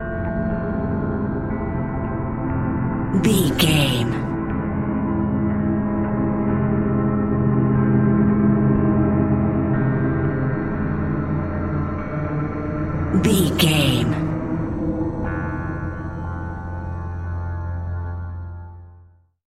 Ionian/Major
F♯
industrial
dark ambient
EBM
drone
experimental
synths